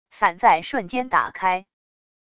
伞在瞬间打开wav音频